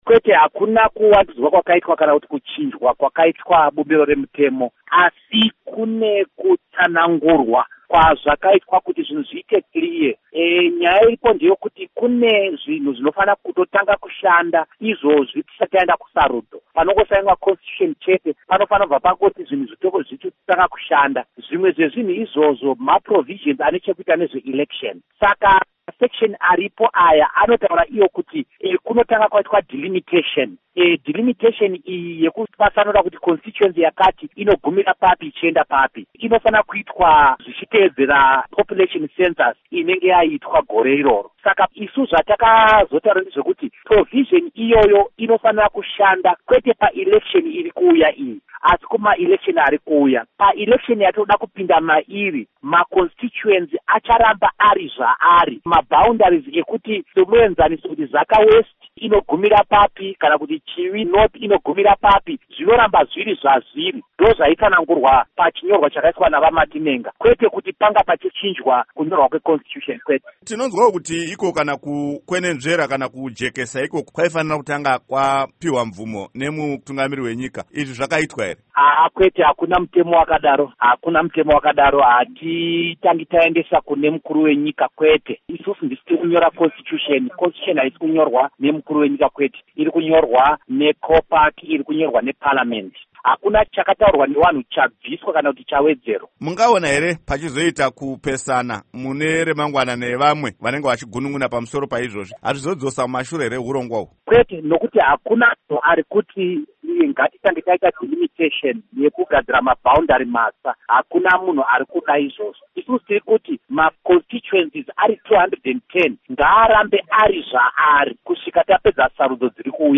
Embed share Hurukuro naVaDouglas Mwonzora by VOA Embed share The code has been copied to your clipboard.